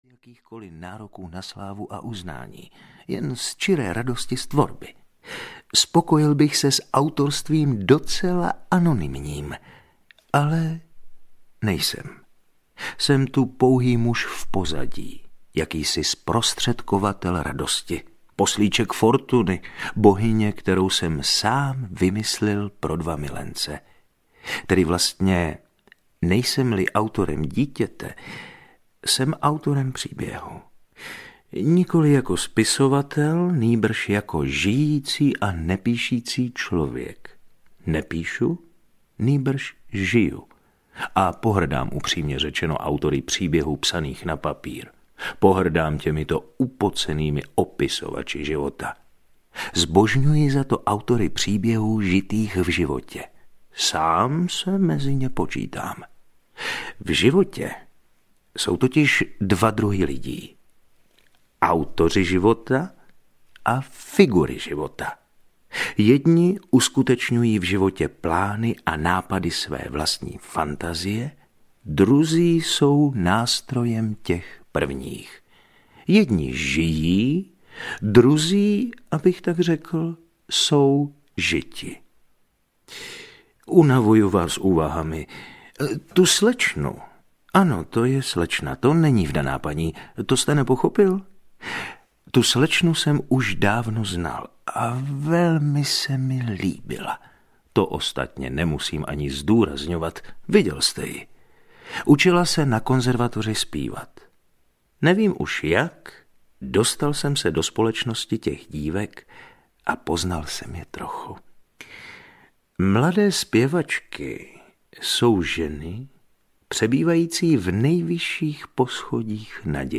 Směšné lásky audiokniha
Ukázka z knihy
Jejich význam daleko přesahuje svět literatury.Povídky Já truchlivý Bůh, Zlaté jablko věčné touhy, Nikdo se nebude smát, Falešný autostop, Symposion, Doktor Havel po dvaceti letech, Ať ustoupí staří mrtví mladým mrtvým, Eduard a BůhNahráno v Českém rozhlase Brno v roce 2008.